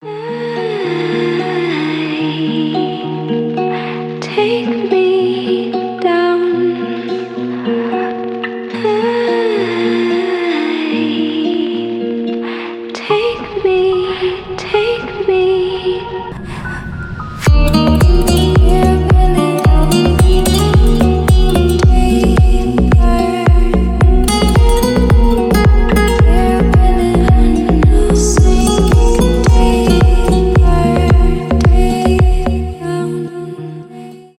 нежные , романтические , поп , deep house
chill
красивый женский голос